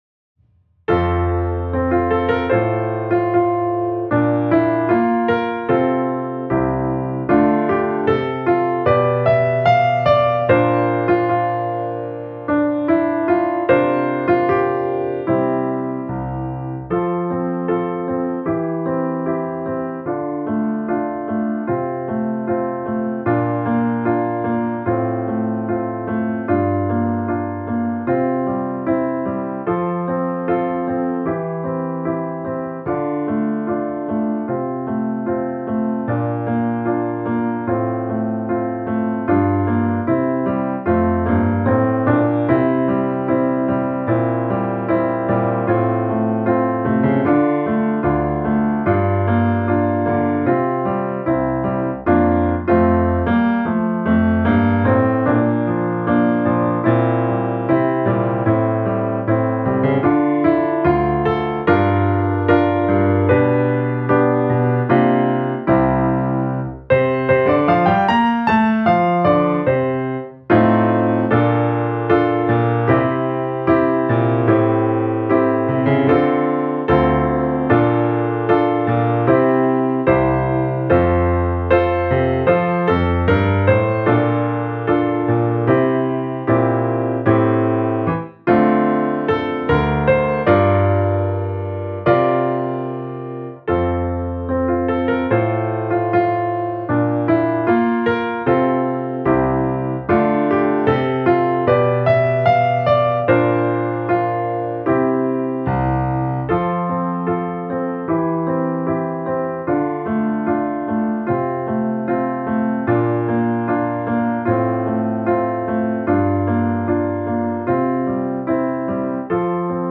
園児や児童の皆様が歌いやすいように編曲したバージョンもございます。
ピアノバージョン